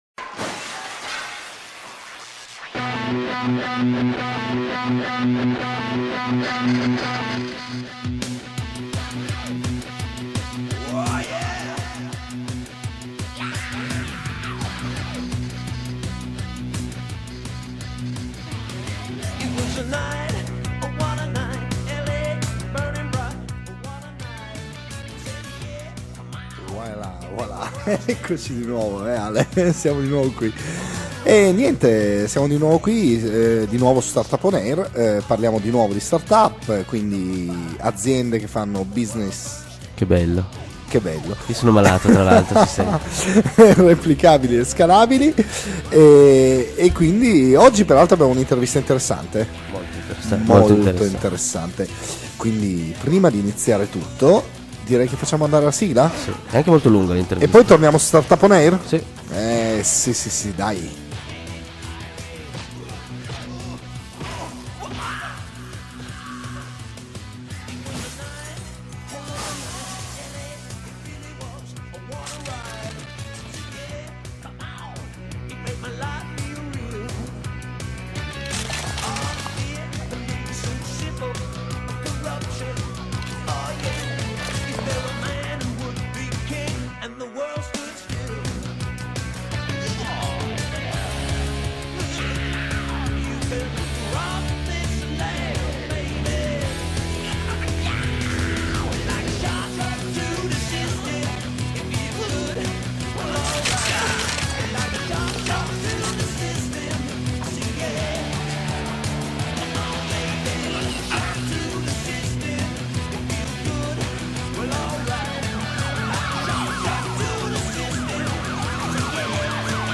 StartupOnAir Quindicesima puntata - Intervista